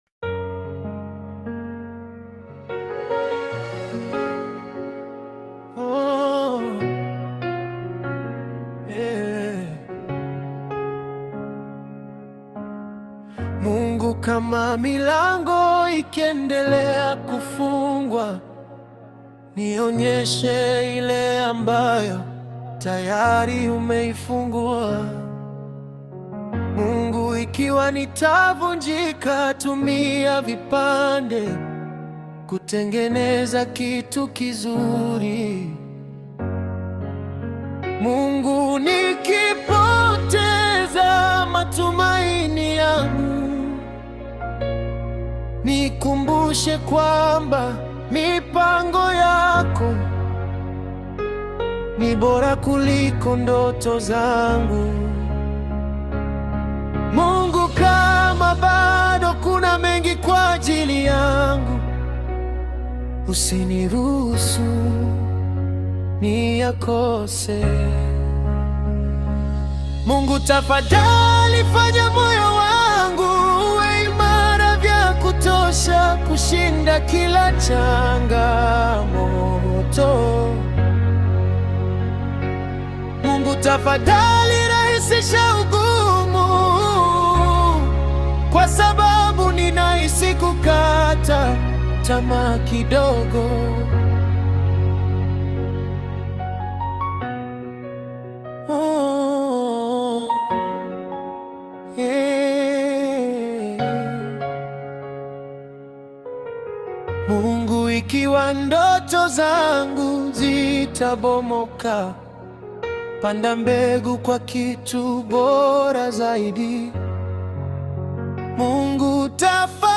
• Genre: Gospel